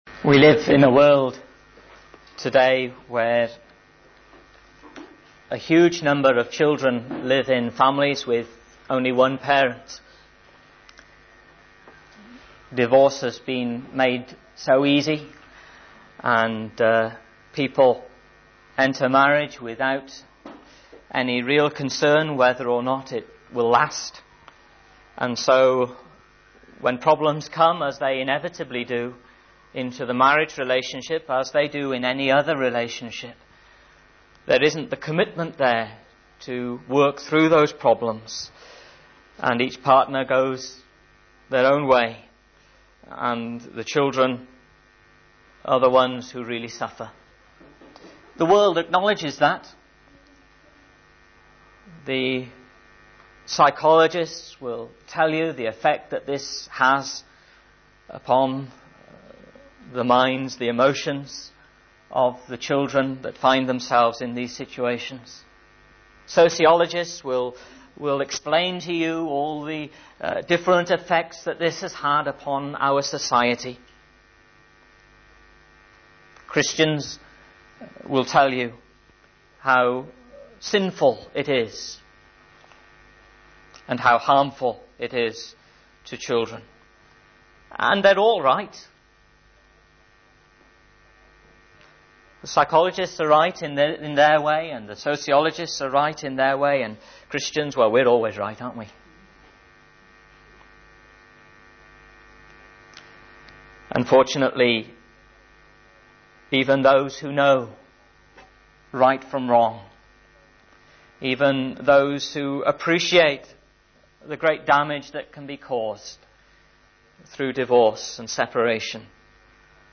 GBC Sermon Archive